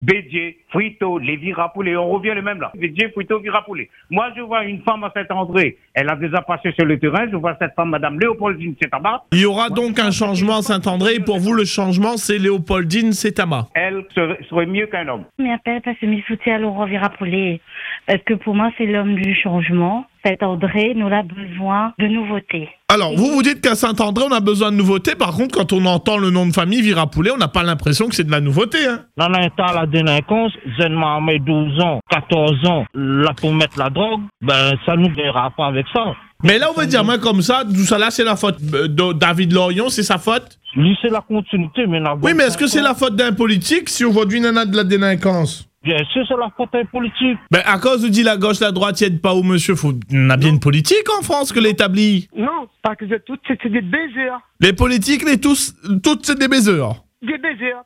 Que pensent vraiment les Réunionnais de la politique et de celles et ceux qui la font ? Nous sommes allés tendre le micro à Saint-André et à Saint-Pierre, pour recueillir l’avis d’un petit échantillon de la population.
Et comme vous allez l’entendre, les réactions sont aussi variées que spontanées.
Entre espoirs déçus, promesses non tenues et sentiment de déconnexion avec la réalité du terrain, ce micro-trottoir dresse le portrait d’une population partagée, oscillant entre engagement citoyen et désillusion :